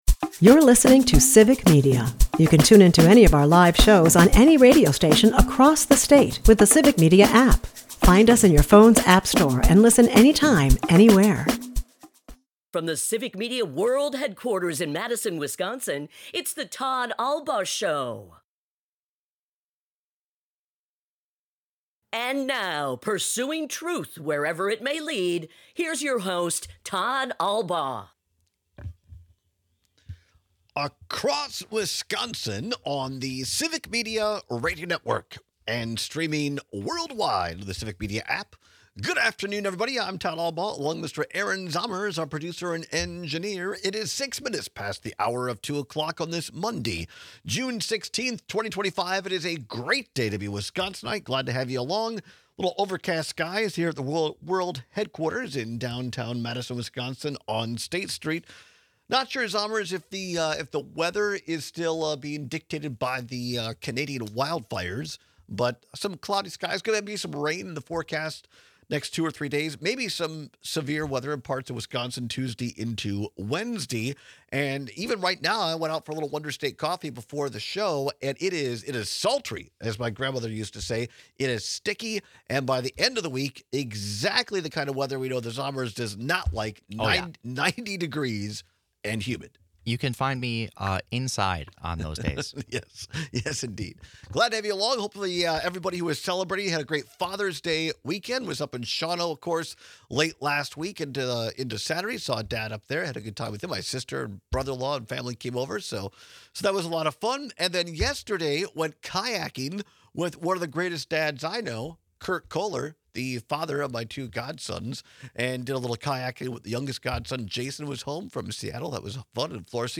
At the bottom of the hour, State Senator Kelda Roys returns for her weekly Joint Finance Committee update.